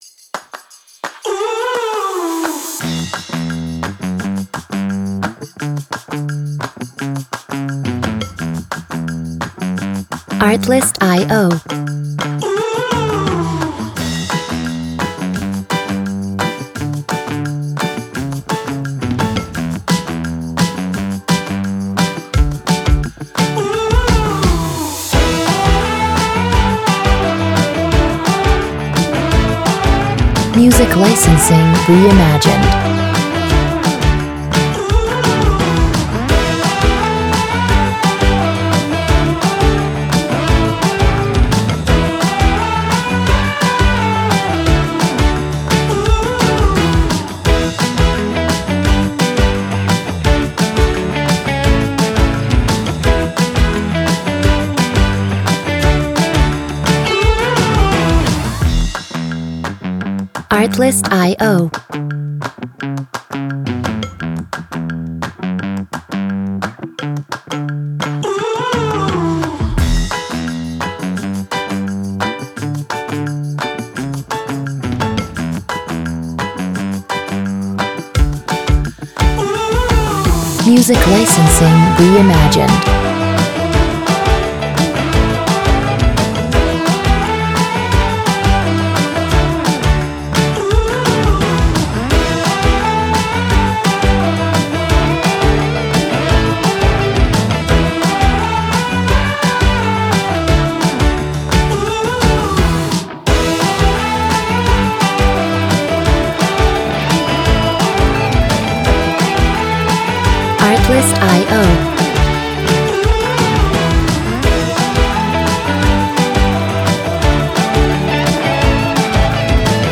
Montage